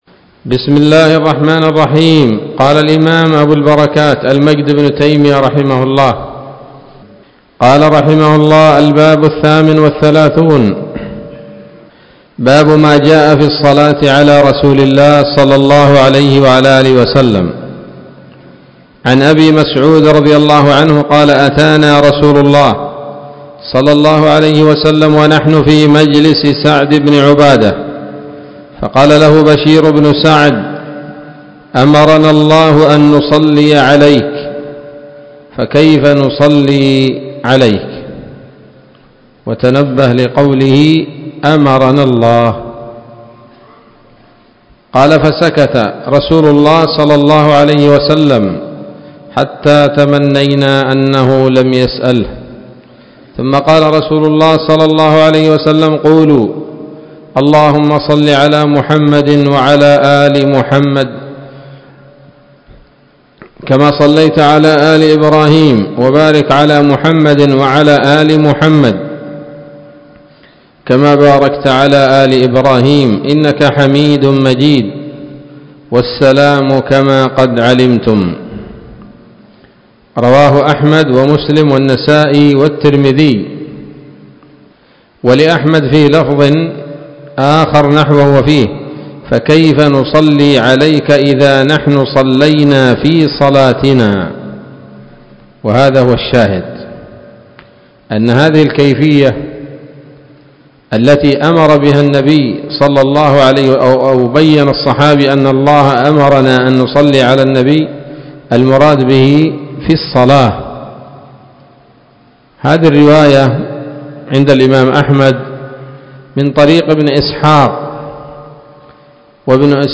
الدرس التاسع والسبعون من أبواب صفة الصلاة من نيل الأوطار